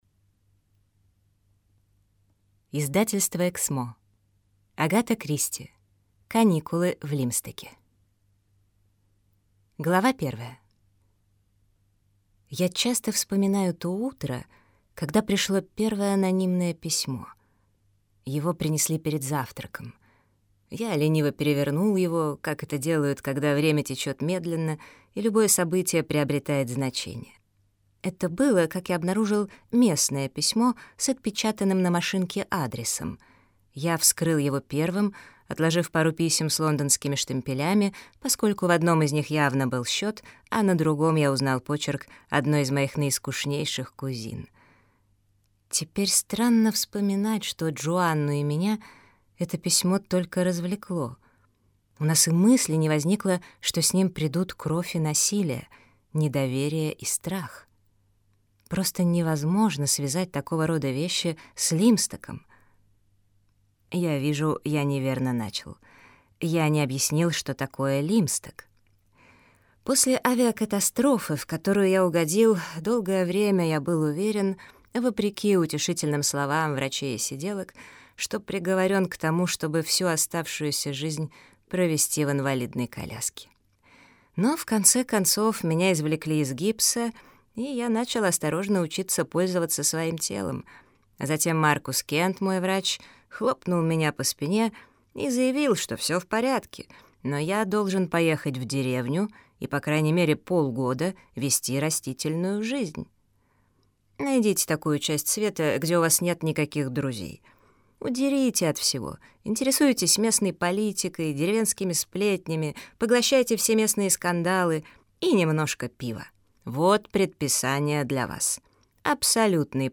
Аудиокнига Каникулы в Лимстоке - купить, скачать и слушать онлайн | КнигоПоиск